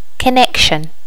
Additional sounds, some clean up but still need to do click removal on the majority.
connection.wav